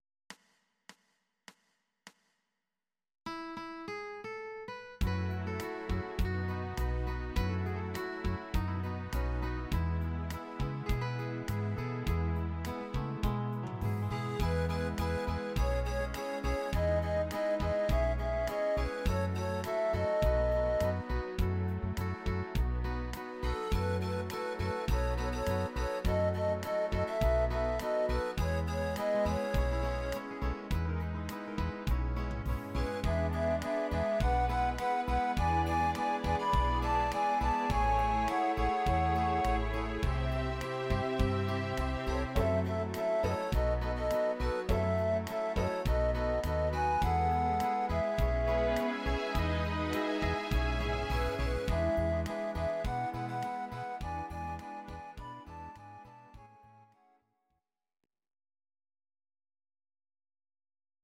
Audio Recordings based on Midi-files
Oldies, Duets, 1960s